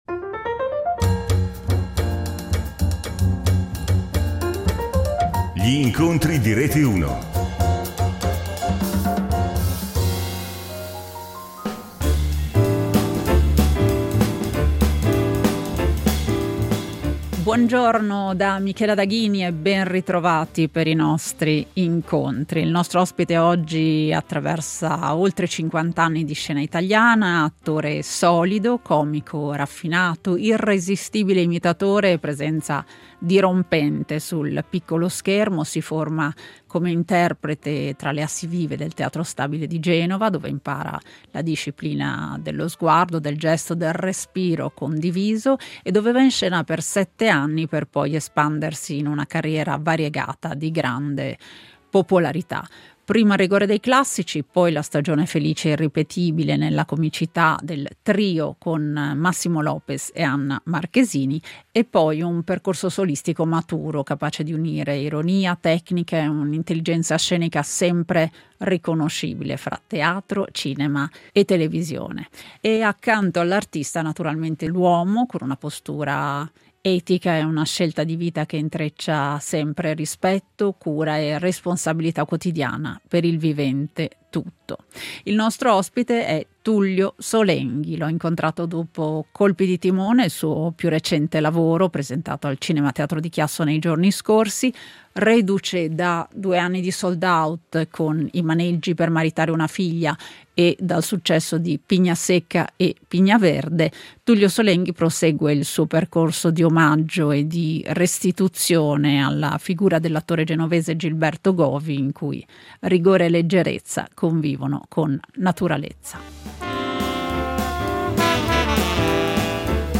Lo incontriamo al Cinema Teatro di Chiasso dopo Colpi di Timone , il suo più recente spettacolo.